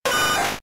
Cri de Sabelette K.O. dans Pokémon Diamant et Perle.